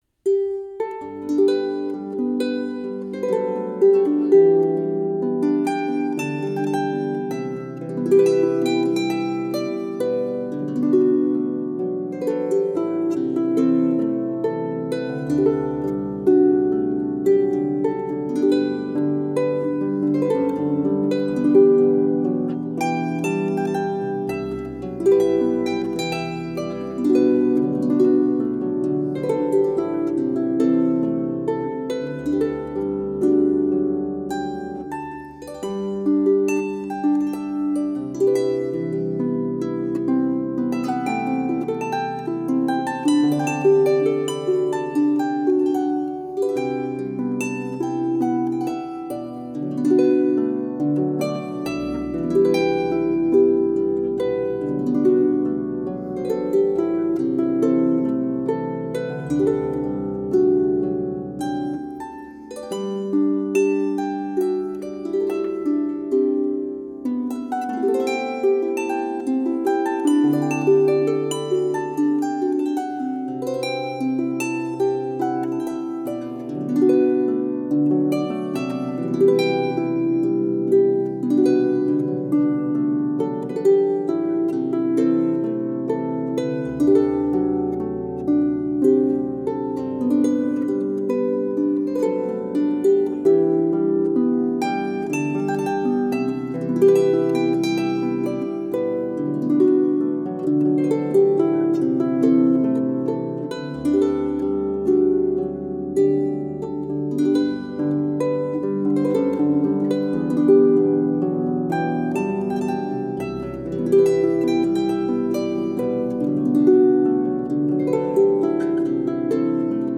CD MUSICA CELTICA